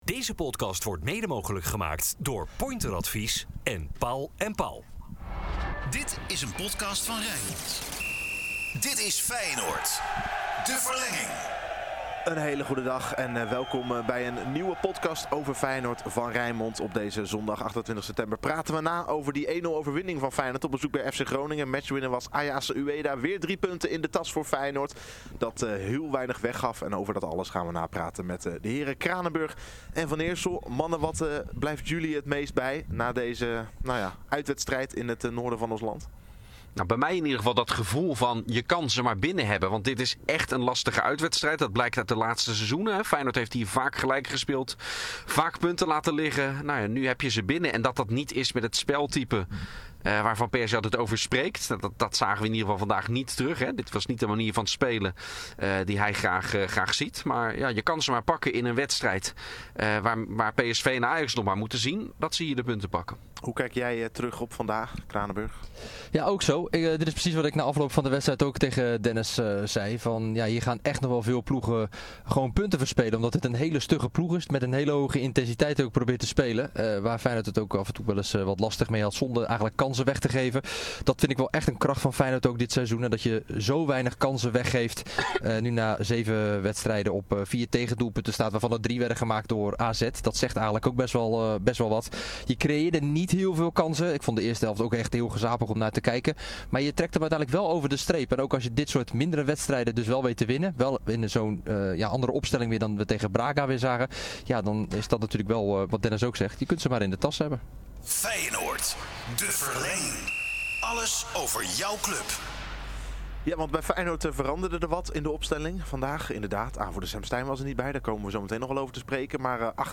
In de podcast Feyenoord: De Verlenging werd de wedstrijd besproken door verslaggevers